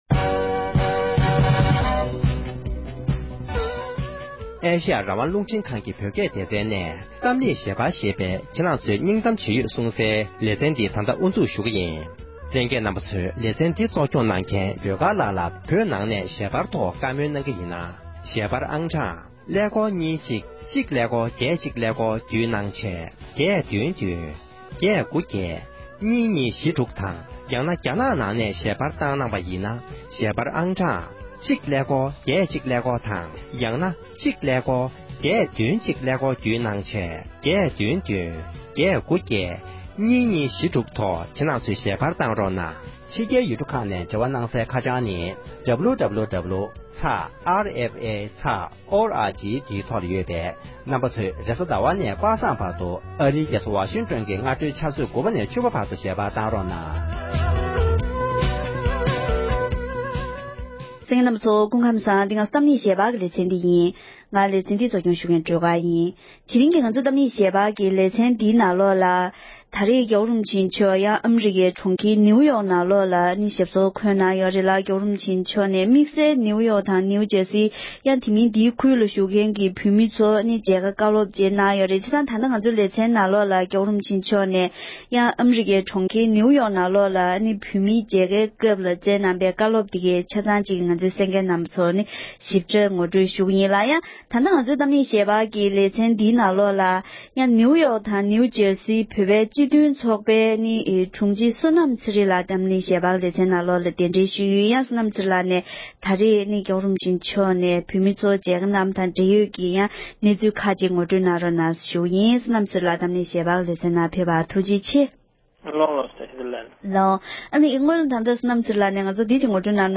འབྲེལ་ཡོད་མི་སྣར་གླེང་མོལ་ཞུས་པར་གསན་རོགས༎